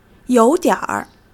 you3-dian3-r.mp3